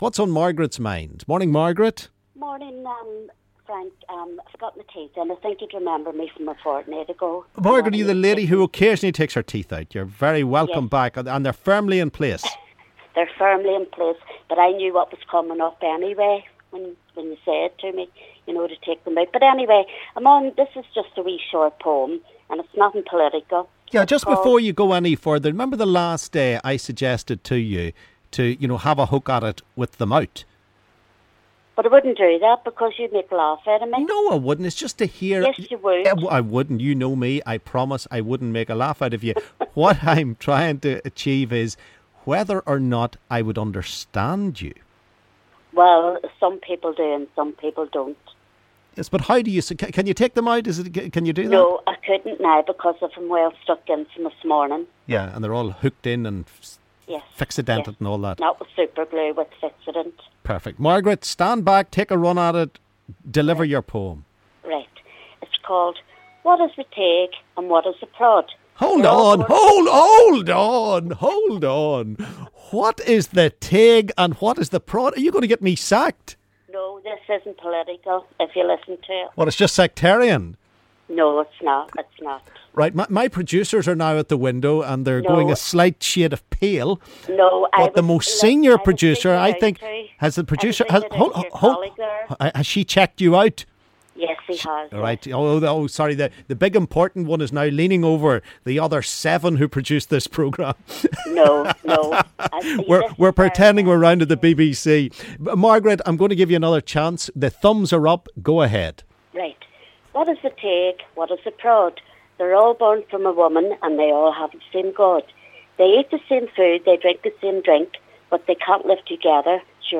Caller
reads a poem